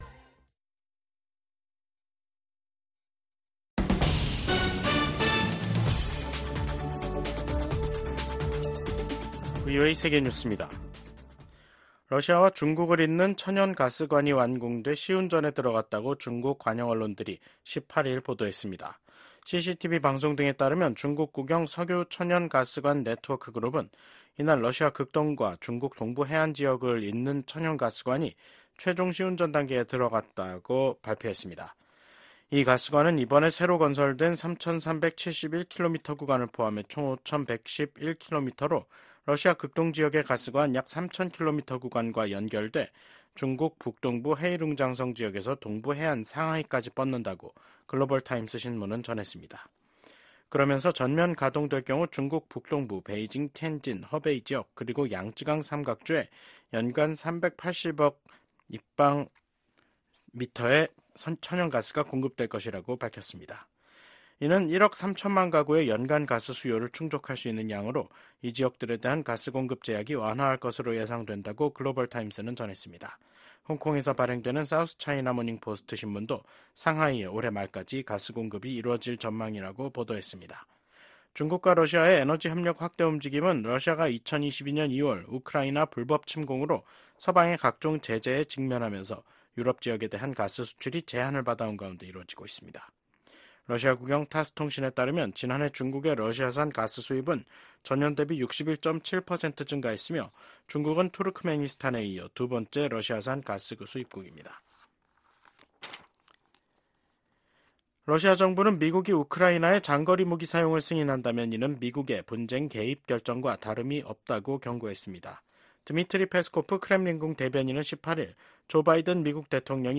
VOA 한국어 간판 뉴스 프로그램 '뉴스 투데이', 2024년 11월 18일 3부 방송입니다. 미국과 한국, 일본의 정상이 북한군의 러시아 파병을 강력히 규탄했습니다. 디미트로 포노마렌코 주한 우크라이나 대사가 VOA와의 단독 인터뷰에서 러시아와 우크라이나 간 격전지인 러시아 쿠르스크에 북한군 장군 7명이 파병됐다고 밝혔습니다.